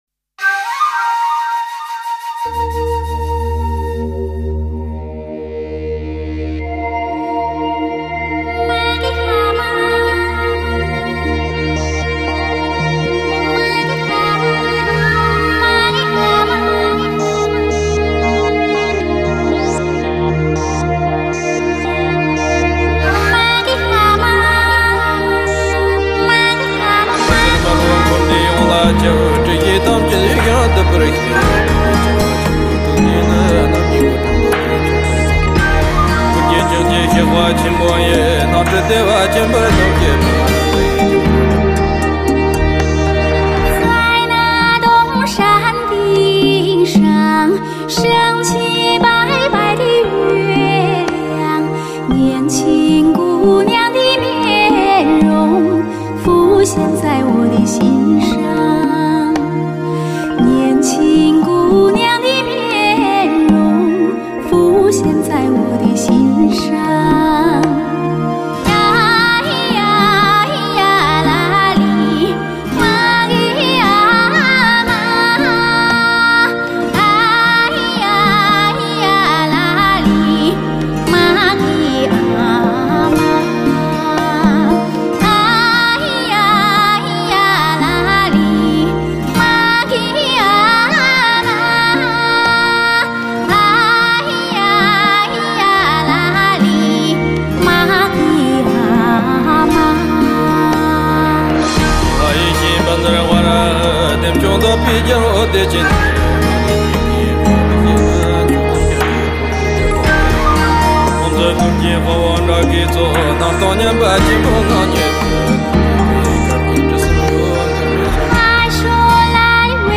空灵、飘渺的歌声引起人们对彼岸的向往。